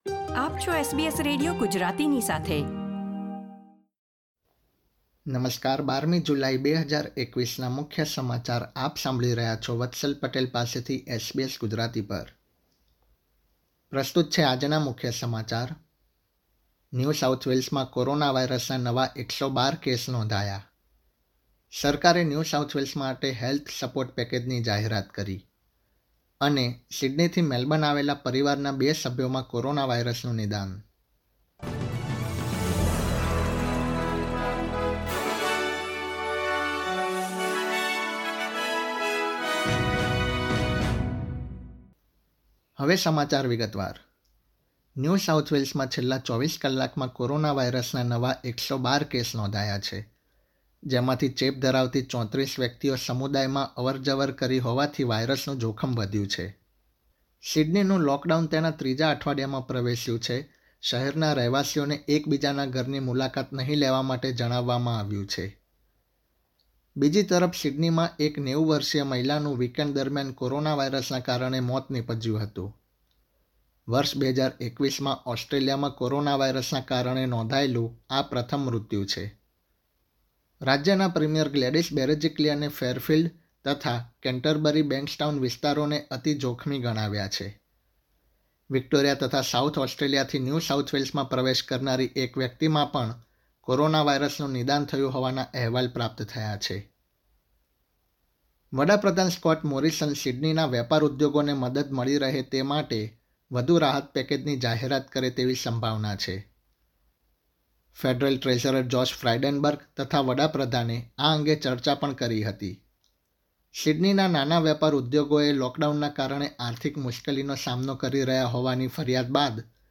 SBS Gujarati News Bulletin 12 July 2021
gujarati_1207_newsbulletin.mp3